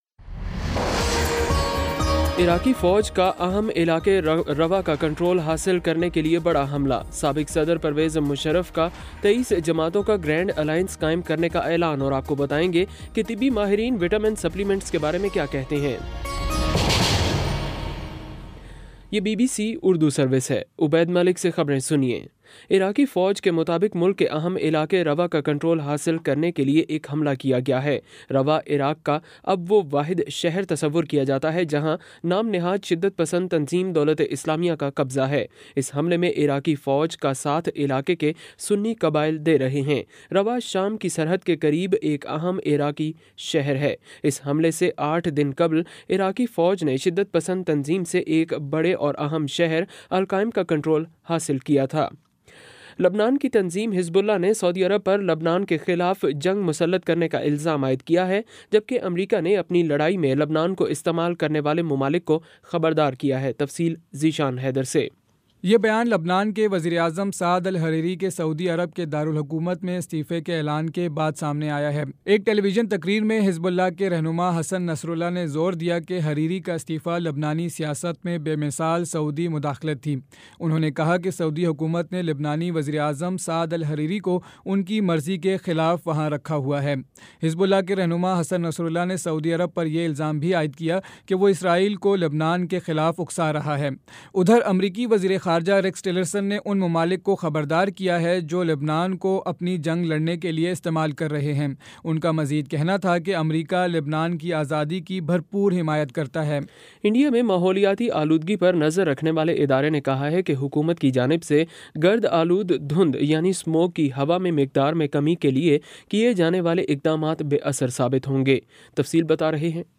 نومبر 11 : شام چھ بجے کا نیوز بُلیٹن